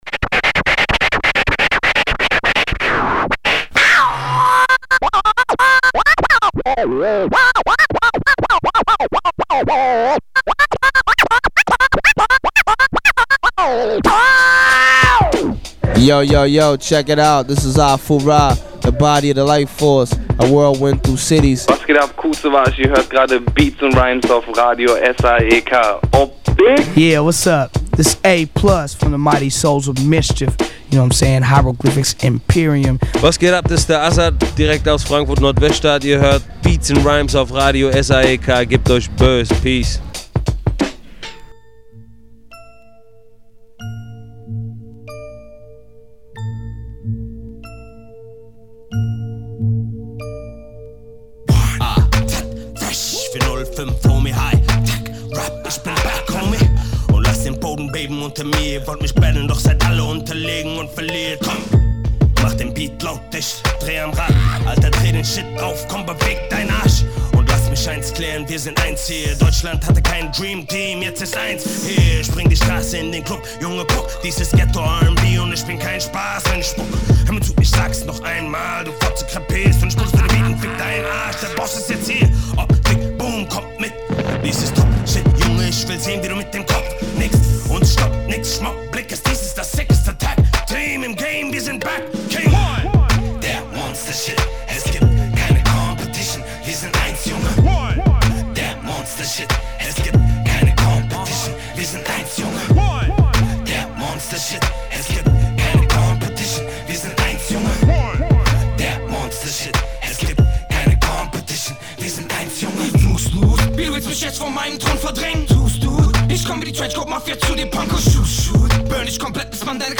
Inhalte: Newz, VA Tips, Musik